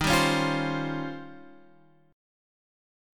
D#13 chord {x 6 5 6 6 8} chord